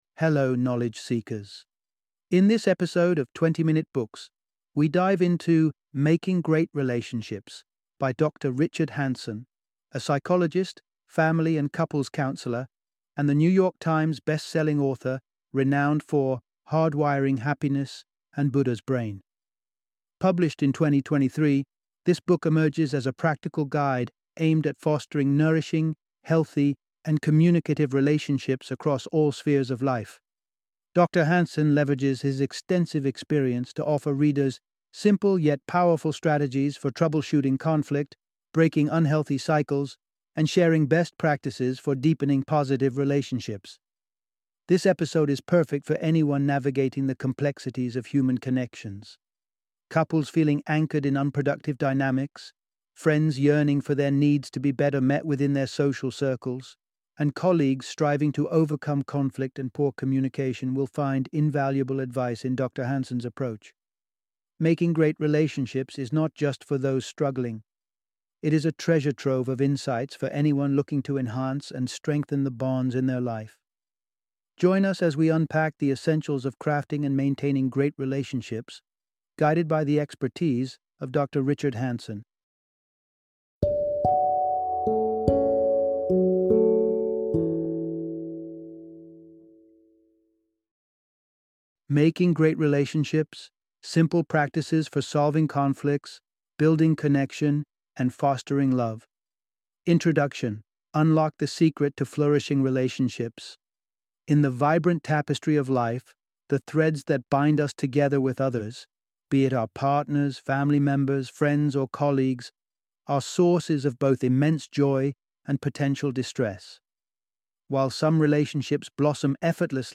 Making Great Relationships - Audiobook Summary